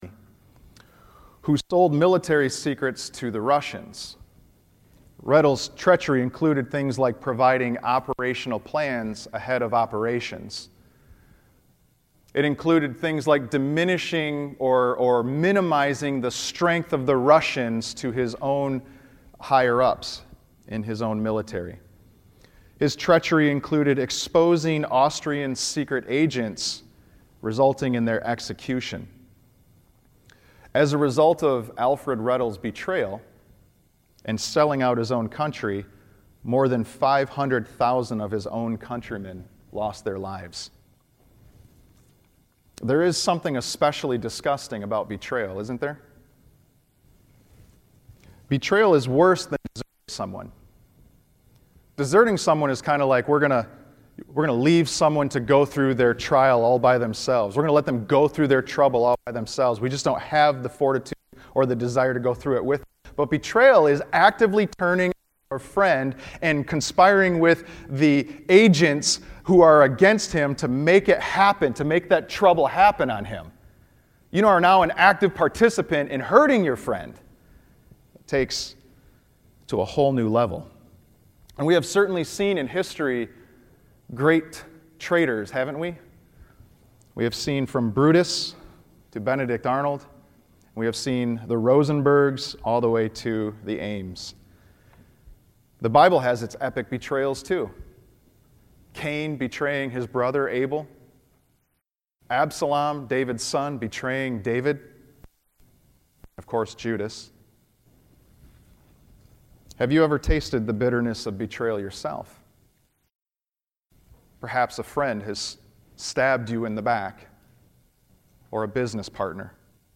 Our sermon title today is “The Bread of Betrayal”. Today we look at the most famous of all betrayers, Judas Iscariot.